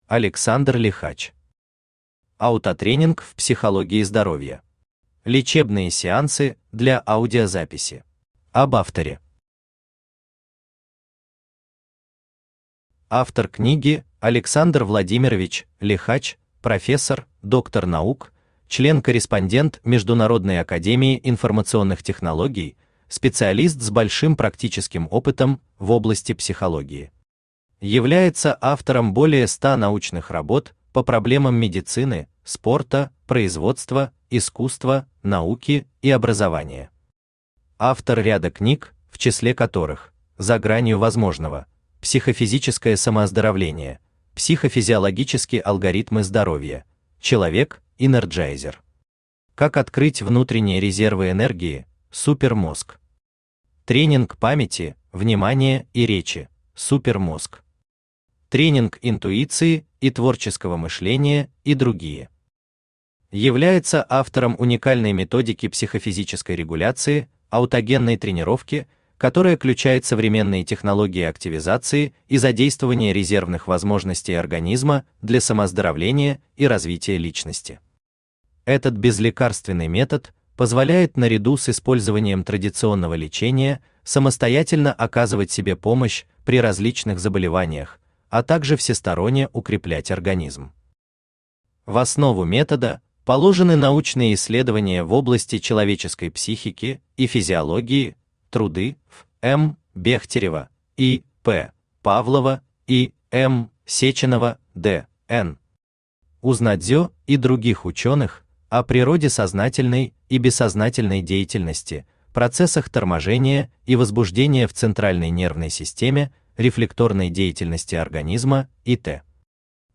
Лечебные сеансы для аудиозаписи Автор Александр Владимирович Лихач Читает аудиокнигу Авточтец ЛитРес.